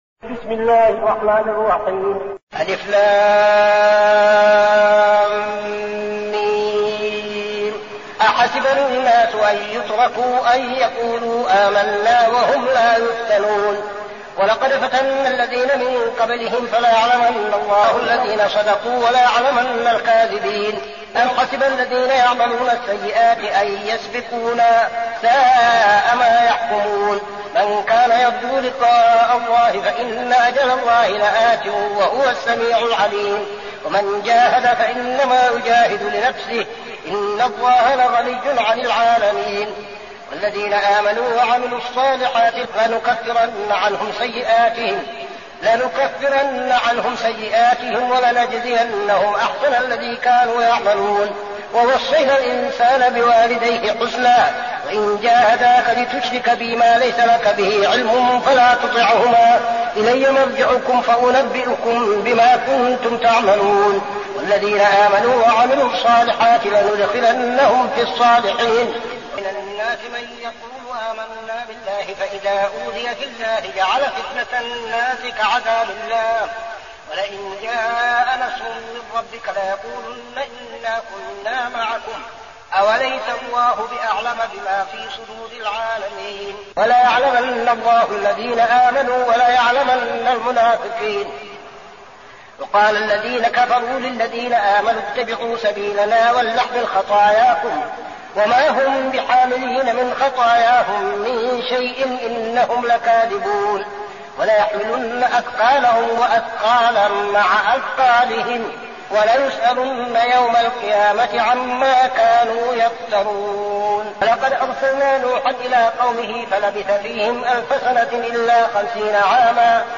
المكان: المسجد النبوي الشيخ: فضيلة الشيخ عبدالعزيز بن صالح فضيلة الشيخ عبدالعزيز بن صالح العنكبوت